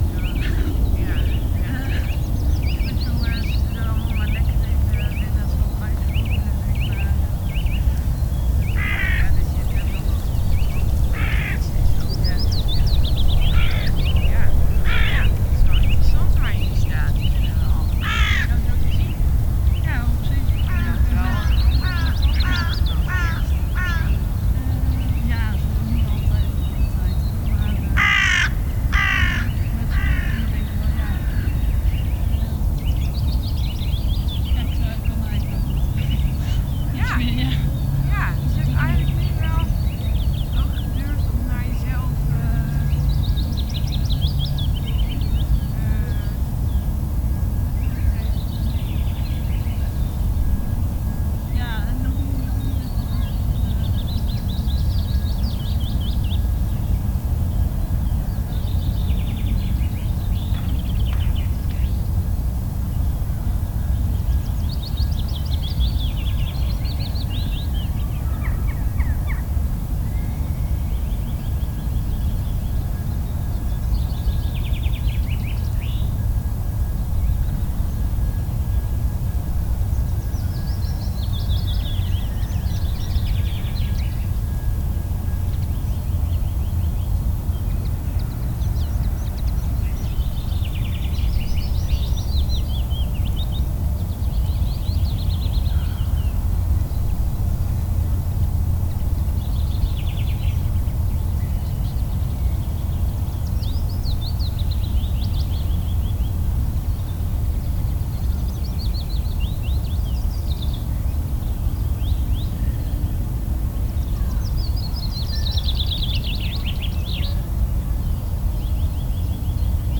people on drifting sand area Loonse en Drunense Duinen Netherlands 1047 am 250404_1067
ambiance ambience ambient atmospheric background-sound birds calm chatting sound effect free sound royalty free Nature